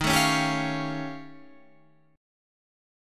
D# 9th Flat 5th